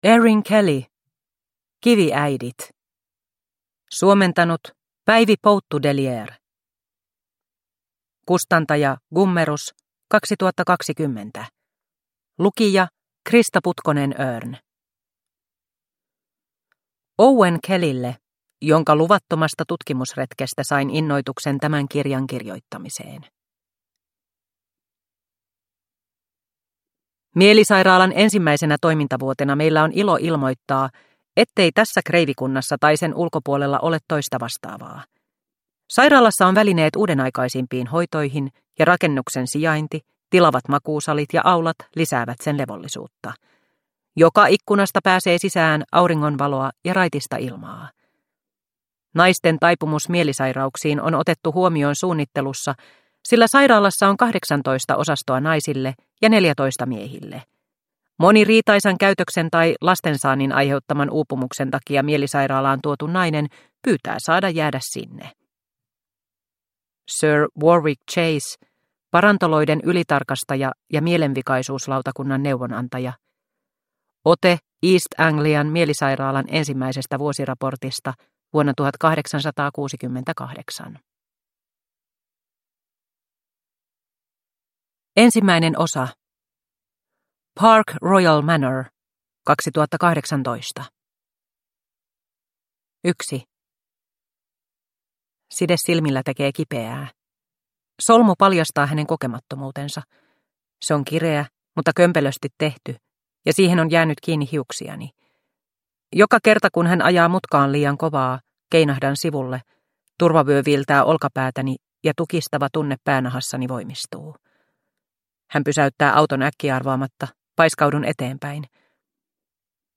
Kiviäidit – Ljudbok – Laddas ner